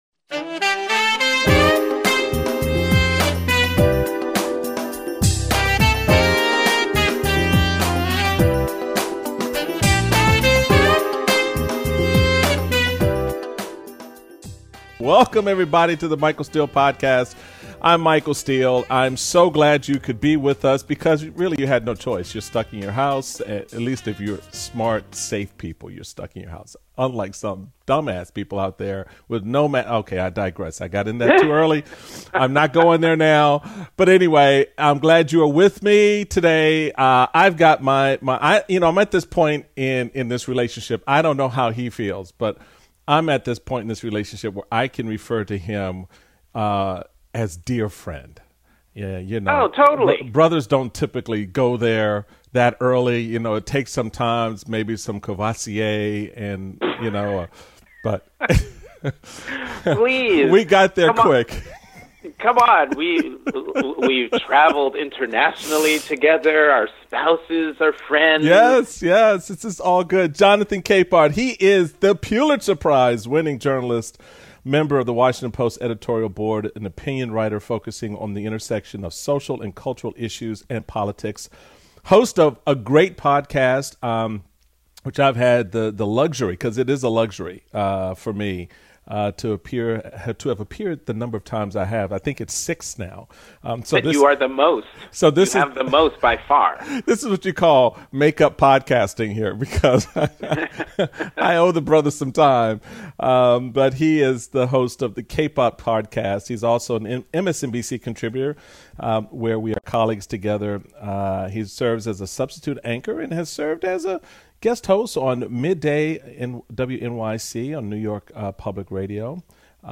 Michael talks with Pulitzer Prize-winning journalist Jonathan Capehart. They talk about the recent killing of George Floyd, the incident where a woman threatened to call police on peaceful bird watcher Christian Cooper, and the racism that exists in America.